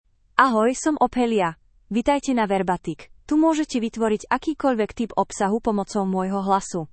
OpheliaFemale Slovak AI voice
Ophelia is a female AI voice for Slovak (Slovakia).
Voice sample
Listen to Ophelia's female Slovak voice.
Female
Ophelia delivers clear pronunciation with authentic Slovakia Slovak intonation, making your content sound professionally produced.